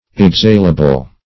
Exhalable \Ex*hal"a*ble\, a. Capable of being exhaled or evaporated.
exhalable.mp3